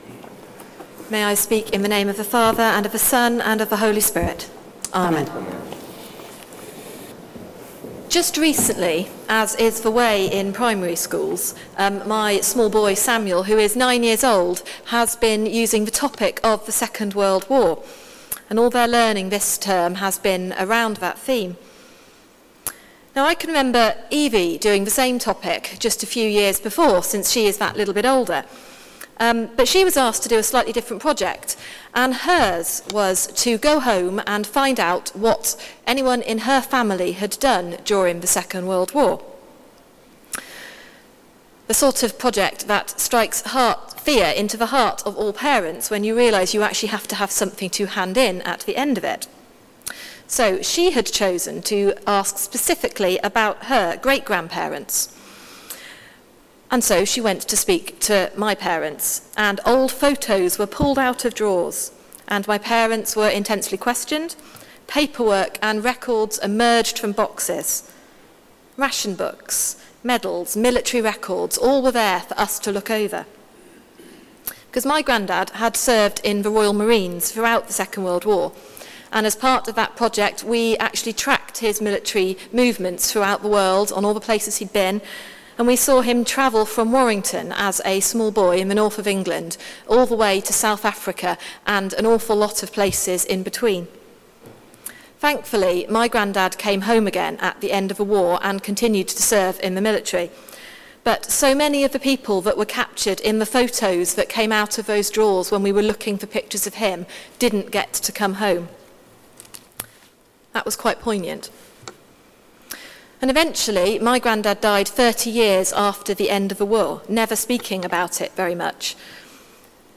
Sermon: Children of the resurrection | St Paul + St Stephen Gloucester